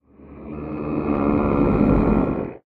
Minecraft Version Minecraft Version snapshot Latest Release | Latest Snapshot snapshot / assets / minecraft / sounds / mob / warden / agitated_6.ogg Compare With Compare With Latest Release | Latest Snapshot
agitated_6.ogg